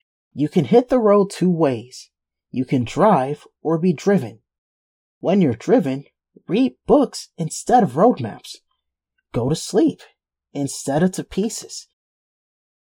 This second file shows what can happen if you process the audio to remove excessive room tone. The frequencies that have been removed give the voice a metallic/unnatural sound which makes this unacceptable for professional projects.